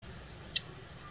Mourning Gecko Chirp Sound Button - Free Download & Play